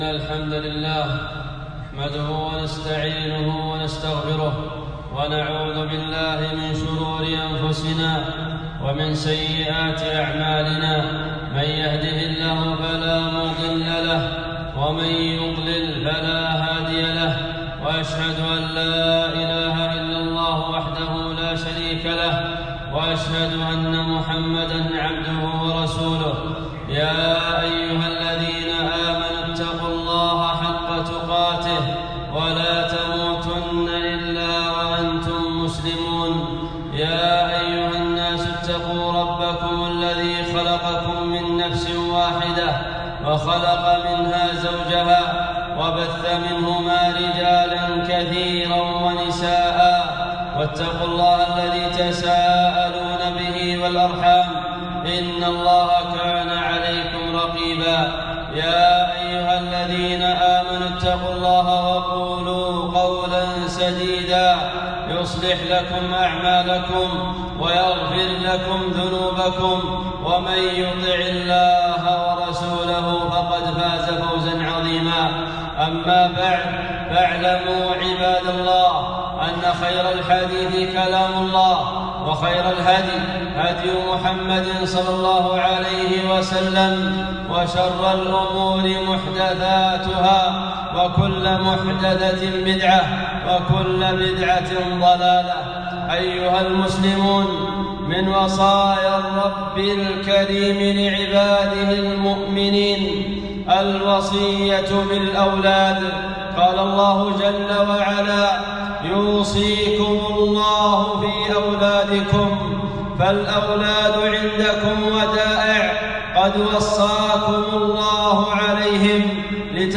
خطبة - تربية الأولاد